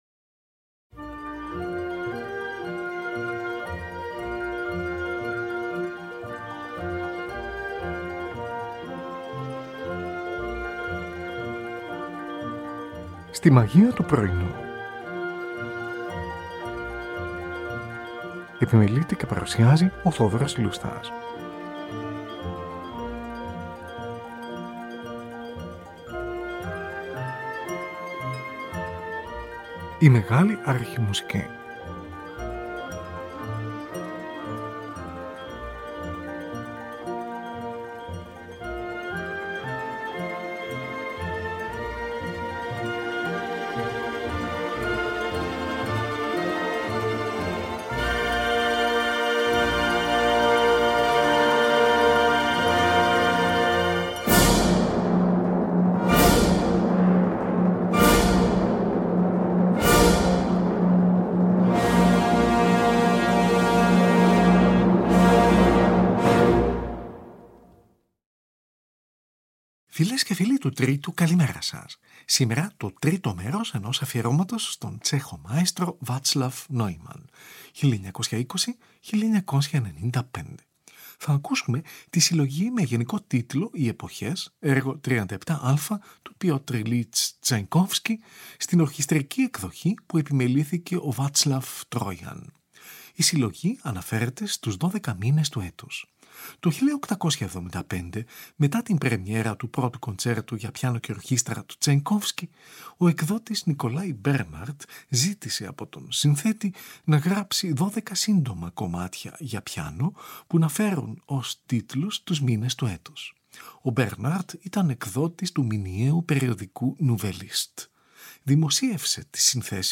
στην ορχηστρική εκδοχή
από δισκογραφική εργασία στις 26 & 27 Οκτωβρίου 1953.